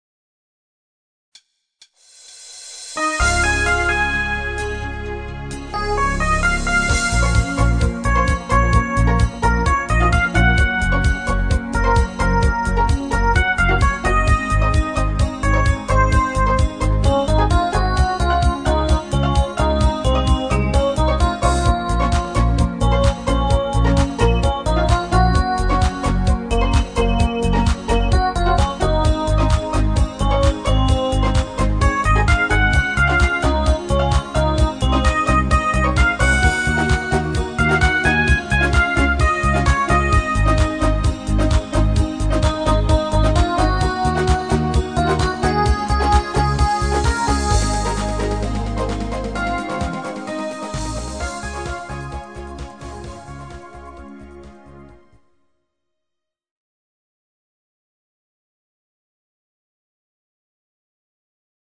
Genre(s): Schlager  |  Rhythmus-Style: Discofox
Fläche (Pad) als alternative Vocalistenspur